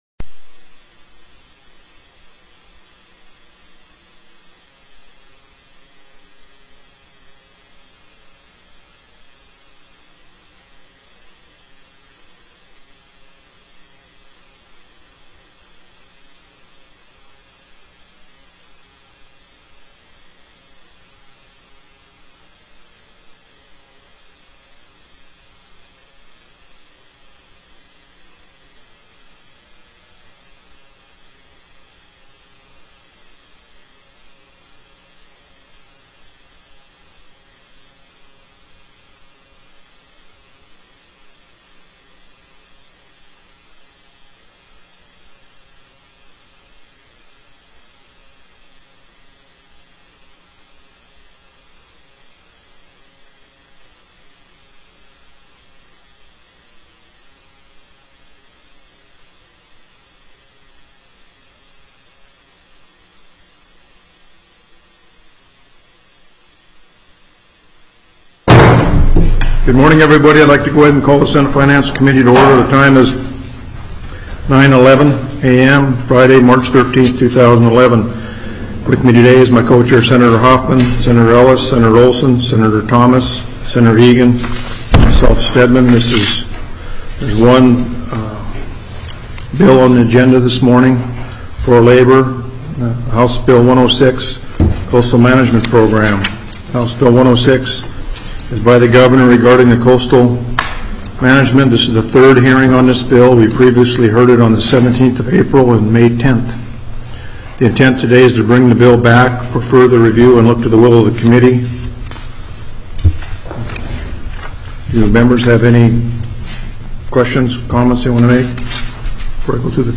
+ Bills Previously Heard/Scheduled TELECONFERENCED
SENATE FINANCE COMMITTEE